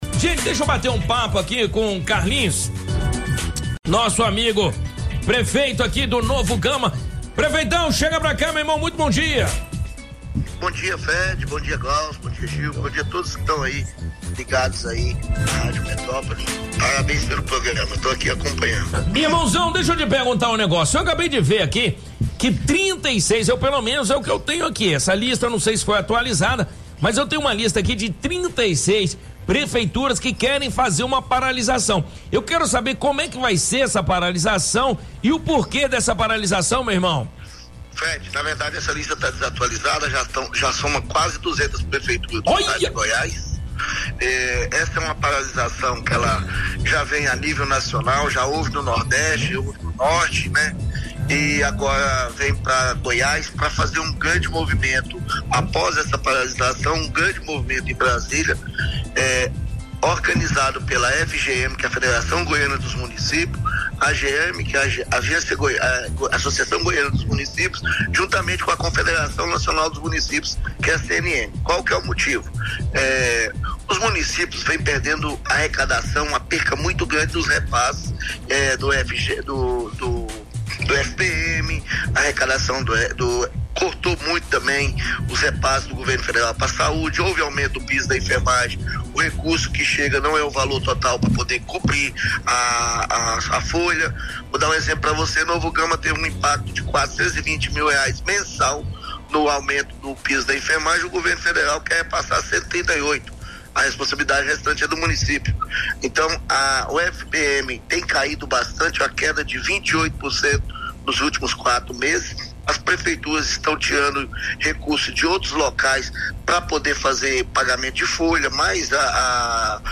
Durante entrevista para Rádio Metrópolis, Prefeito de Novo Gama fala sobre possível paralização de prefeituras goianas em protesto na queda do Repasse do FPN.